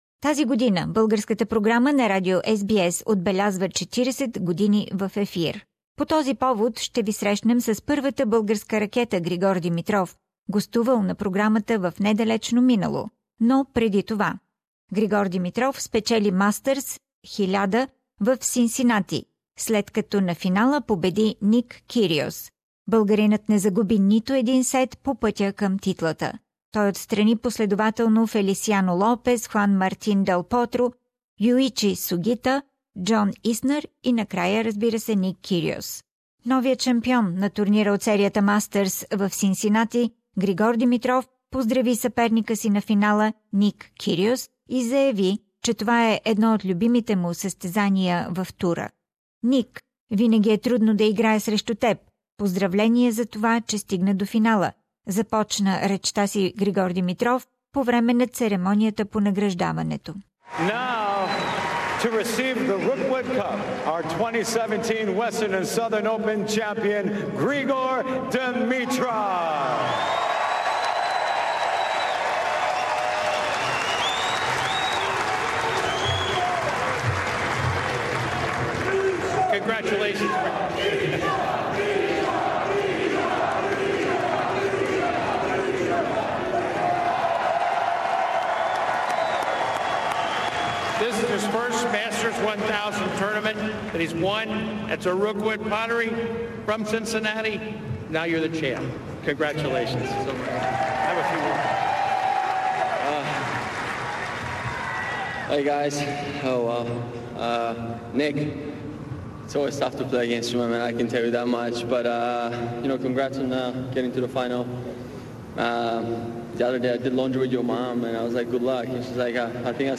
Григор Димитров и Роджер Рашид в откровен разговор по време на АО 2014